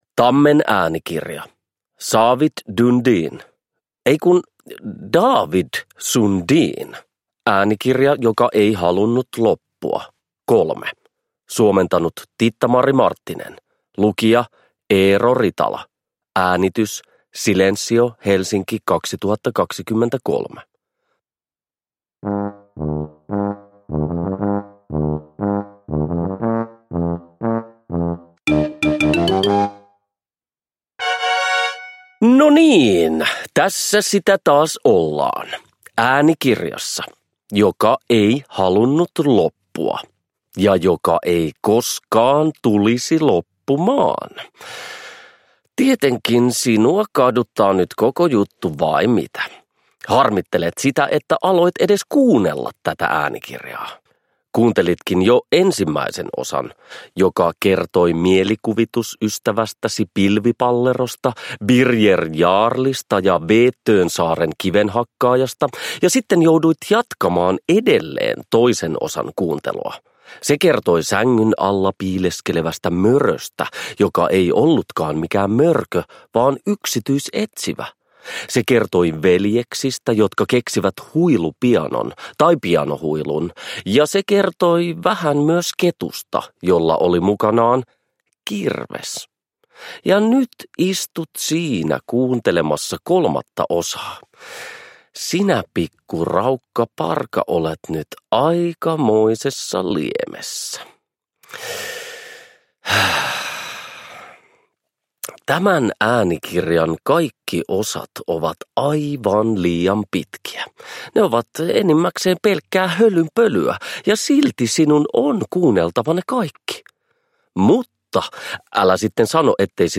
Äänikirja joka ei halunnut loppua 3 – Ljudbok – Laddas ner